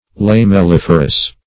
Search Result for " lamelliferous" : The Collaborative International Dictionary of English v.0.48: Lamelliferous \Lam`el*lif"er*ous\, a. [Lamella + -ferous: cf. F. lamellif[`e]re.]
lamelliferous.mp3